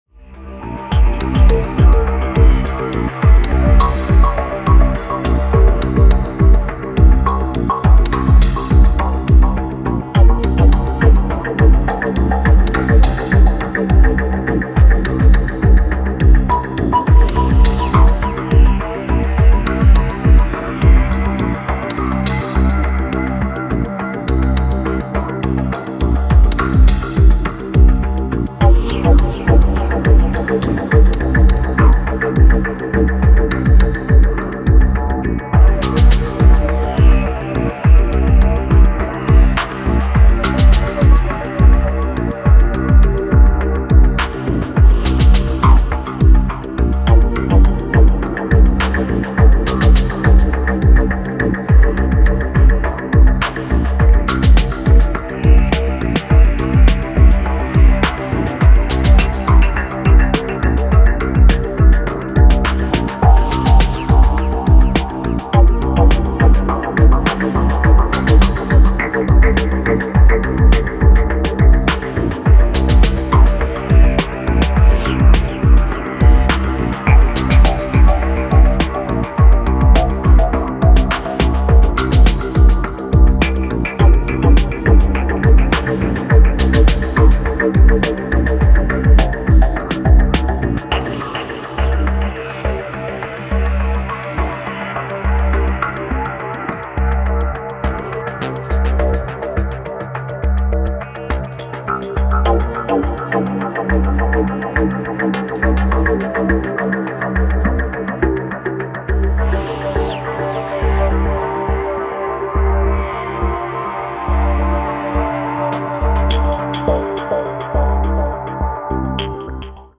Downtempo Dub Techno